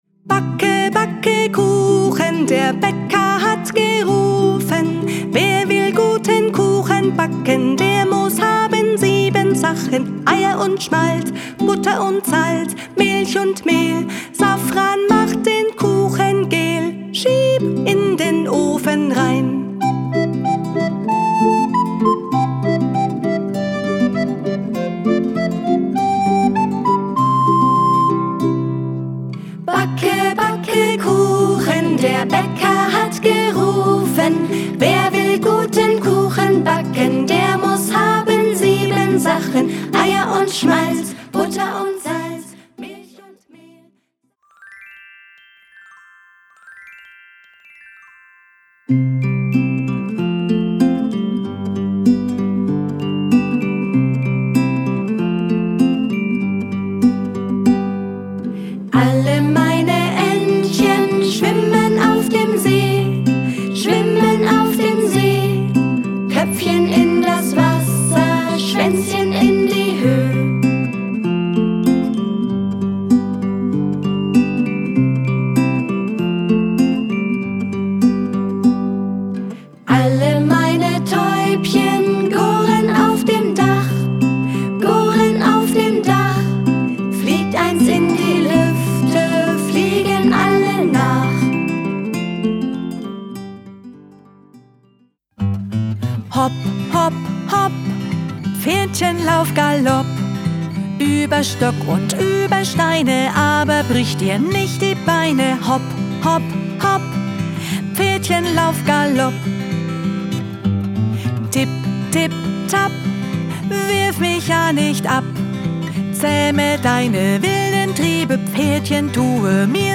Da schwimmen die Entchen, der Bi-Ba-Butzemann tanzt im Haus herum, ein Vogel kommt geflogen und der Bäcker ruft zum Kuchenbacken. Mit den lebendig gesungenen und schön arrangierten Kinderliedern werden die Jüngsten zum Tanzen, Singen und Mitsummen animiert.
Kinder- / Jugendbuch Gedichte / Lieder